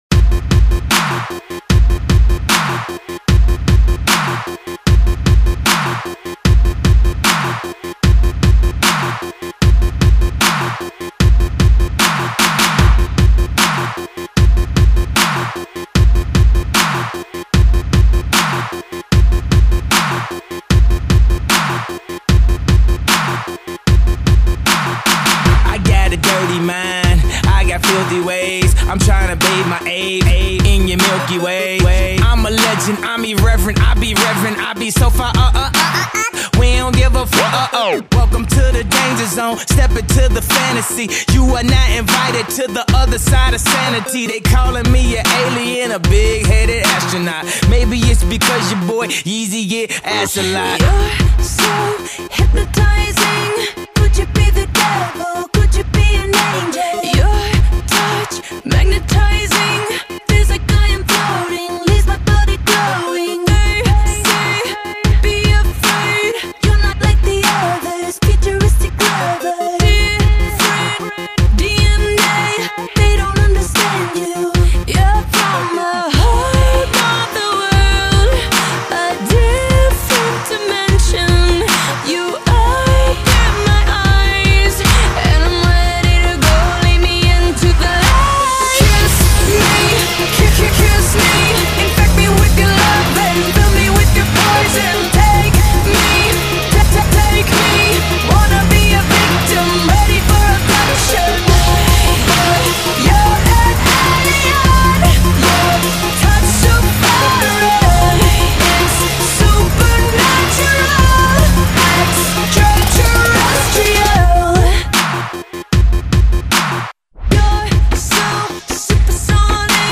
全球最顶级的舞曲 最完美的音乐 最动听的节奏
节奏很好，喜欢，谢谢楼主分享了！
还蛮新潮的爵士舞曲，谢谢一板板  ！
很嗨的舞曲呢   谢谢一版